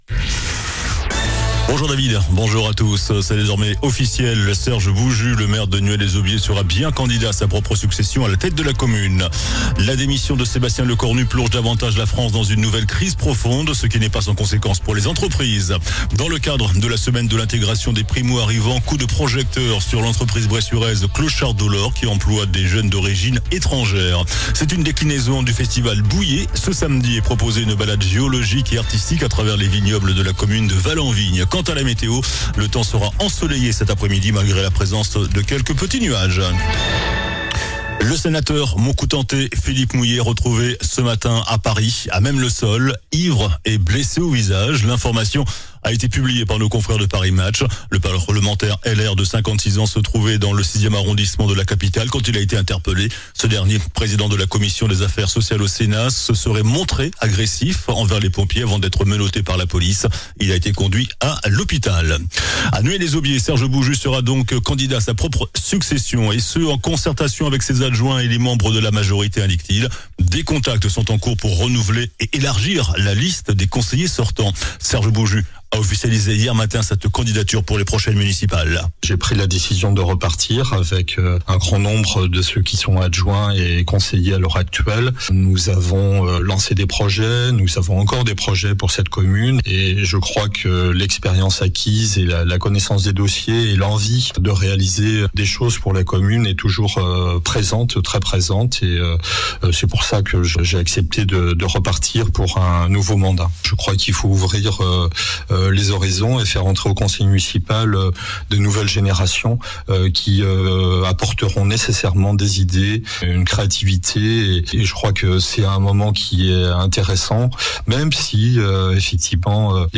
JOURNAL DU MERCREDI 08 OCTOBRE ( MIDI )